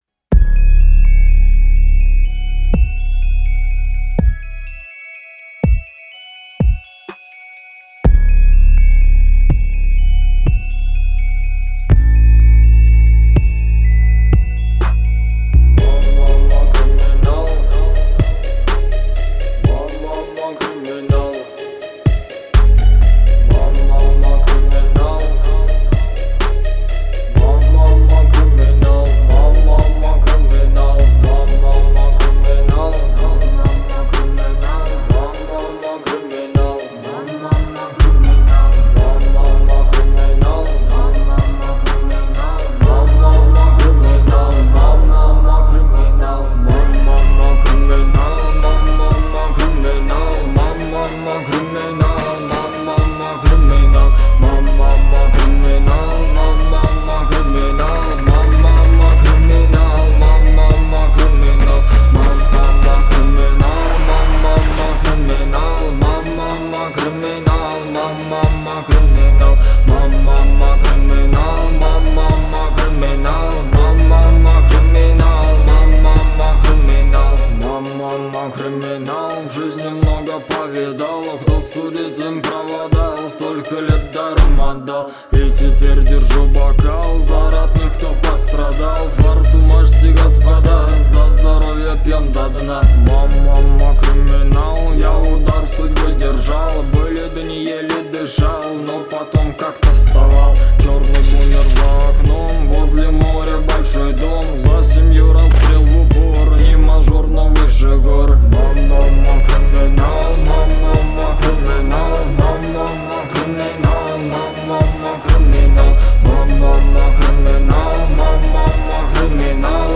• Качество: 320 kbps, Stereo
Tiktok remix